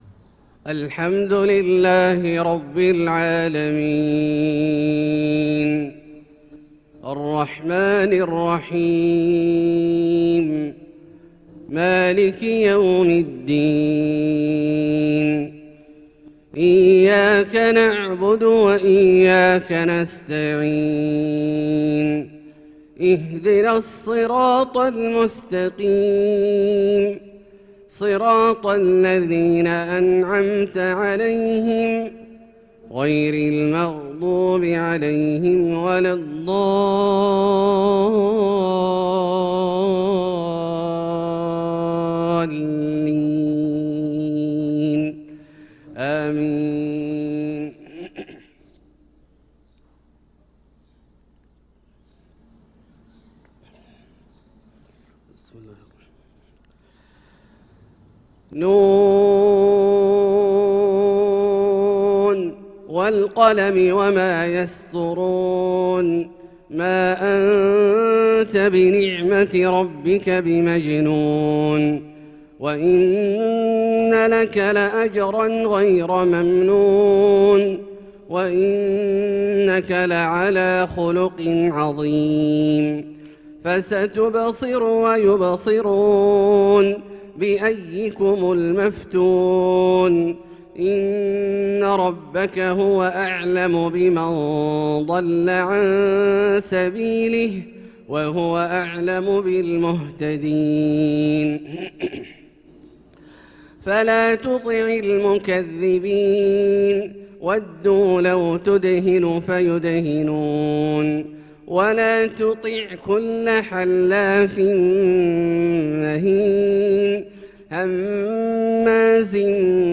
صلاة الفجر للقارئ عبدالله الجهني 16 شوال 1445 هـ